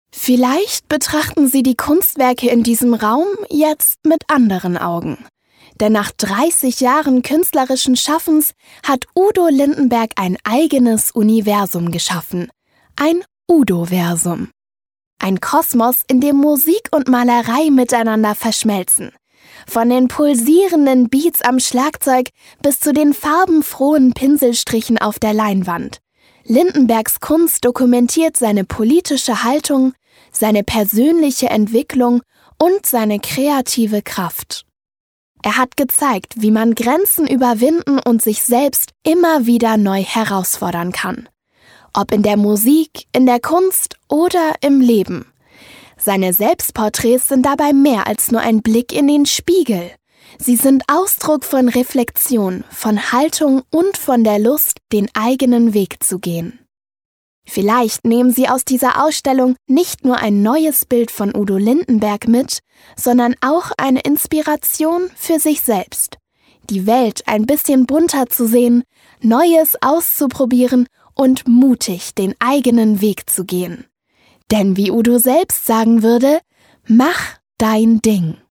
hell, fein, zart, plakativ
Jung (18-30)
Audioguide